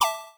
Accept3.wav